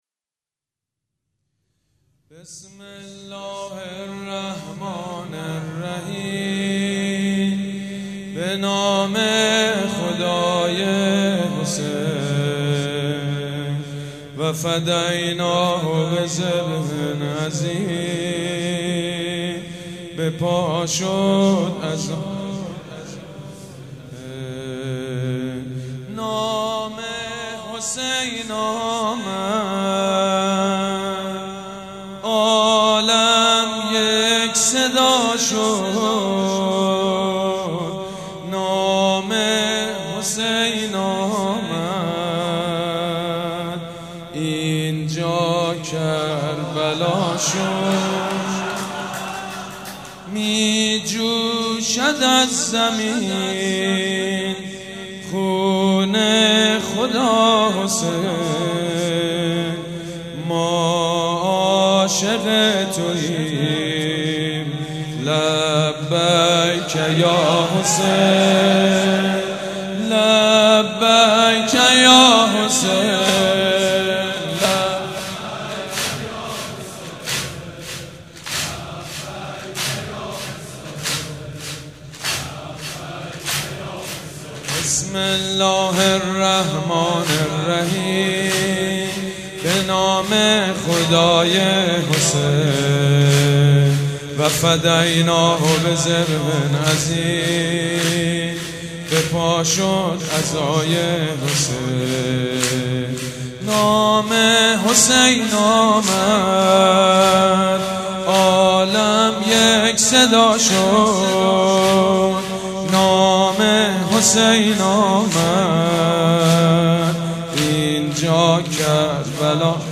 شب اول محرم - به نام نامی حضرت مسلم(ع)
محرم 96 - نوحه - بسم الله الرحمن الرحیم
نوحه سید مجید بنی فاطمه